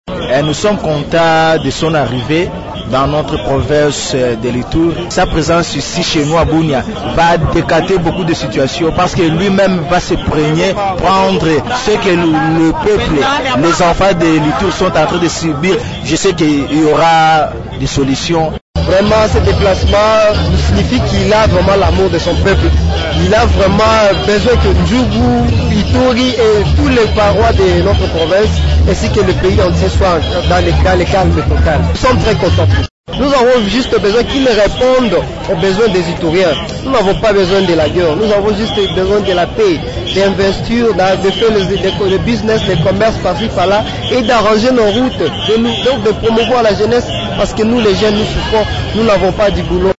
Vous pouvez écouter ici les réactions des habitants de Bunia après l’arrivée de Félix Tshisekedi.